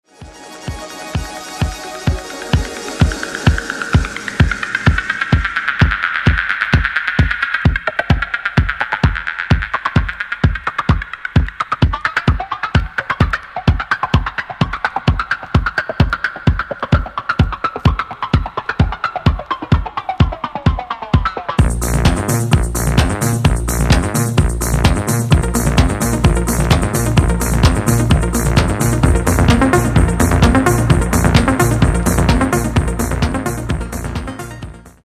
Genere:   Disco | Electronic | Pop